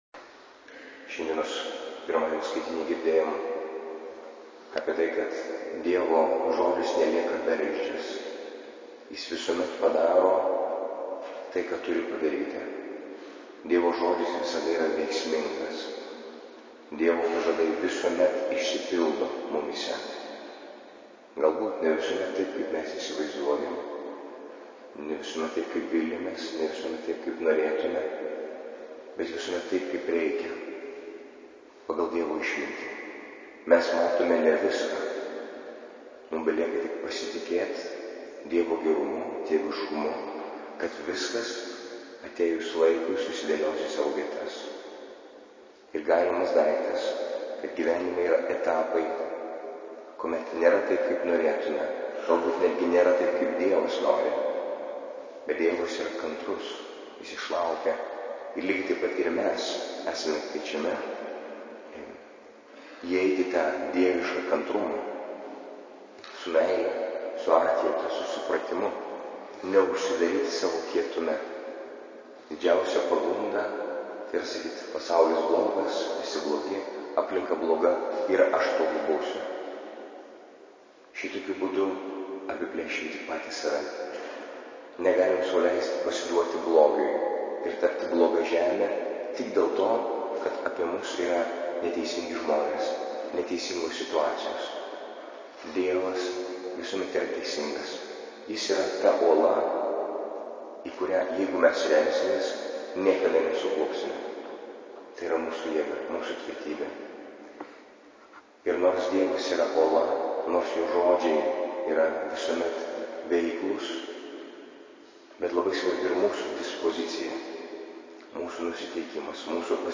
Audio pamokslas Nr1: